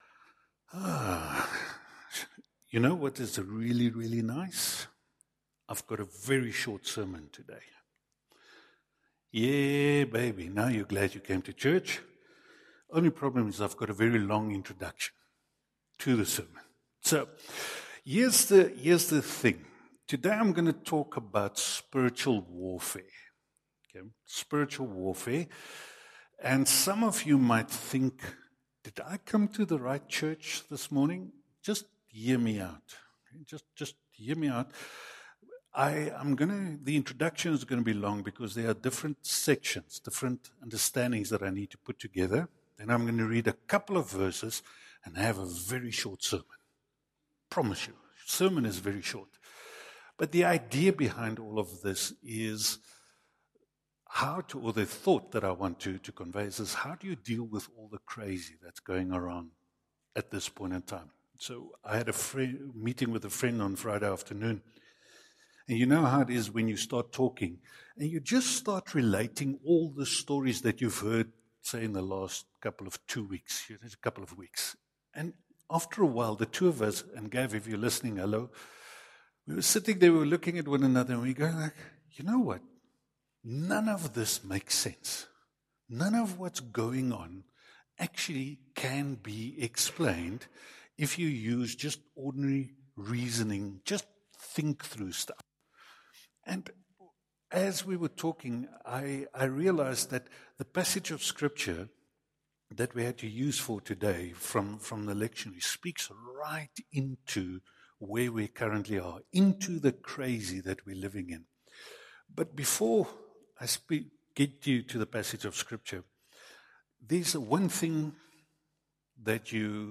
St John’s Presbyterian Church Bloemfontein on 2020-07-05
Sermon